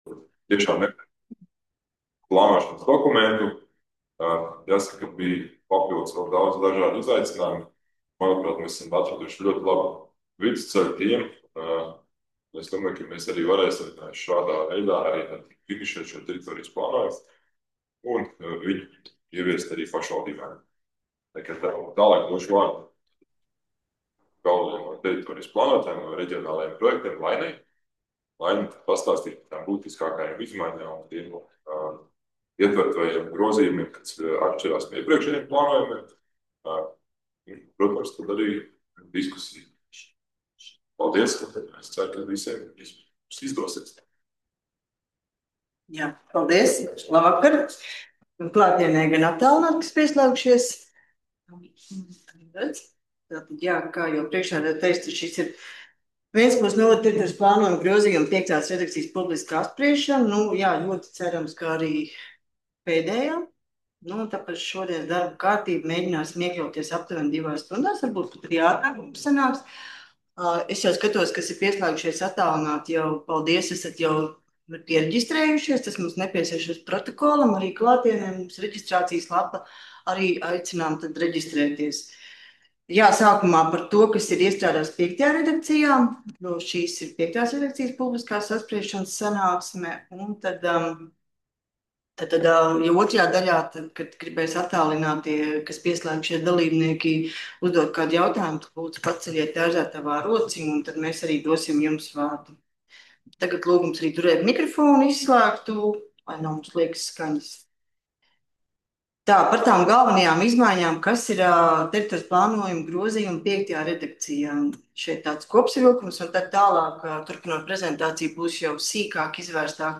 2025. gada 21. jūlija publiskās apspriešanas sanāksmes audio